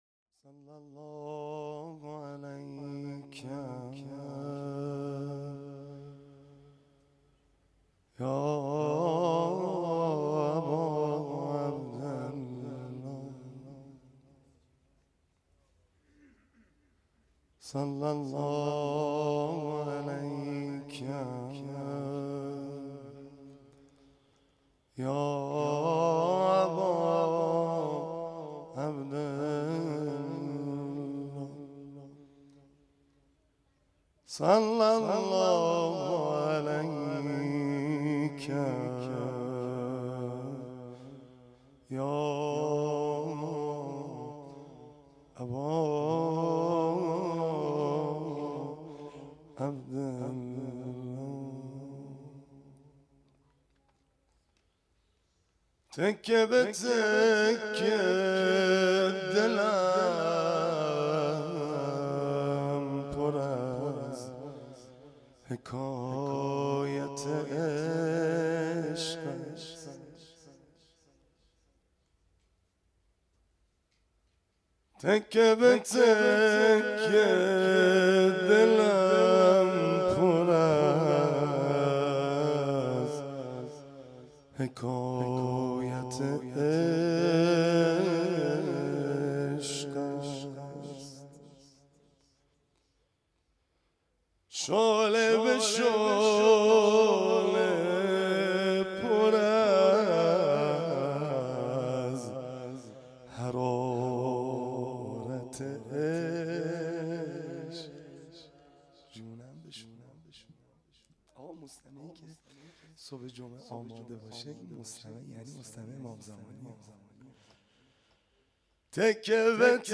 حسینیه کربلا